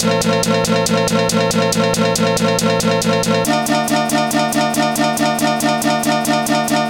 Exodus - Chopped Mandolin.wav